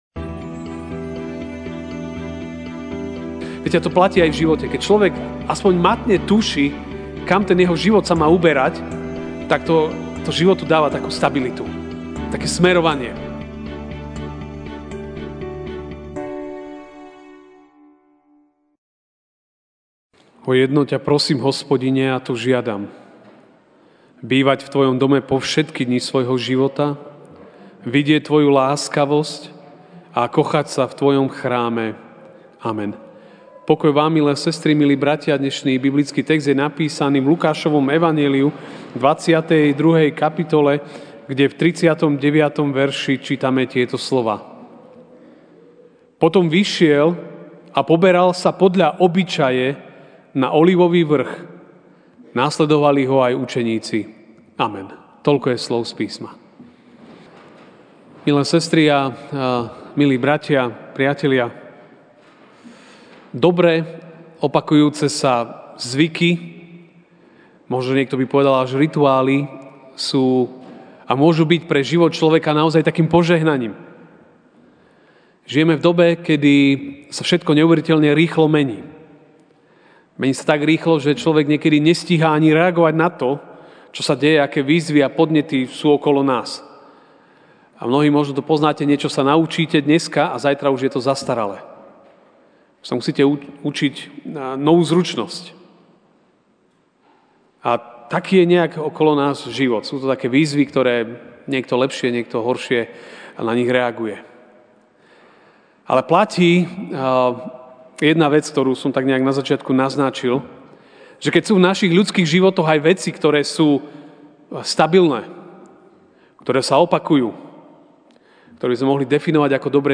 apr 18, 2019 Dobré zvyky MP3 SUBSCRIBE on iTunes(Podcast) Notes Sermons in this Series Večerná kázeň: Dobré zvyky (L 22, 39) Potom vyšiel a poberal sa podľa obyčaje na Olivový vrch; nasledovali Ho aj učeníci.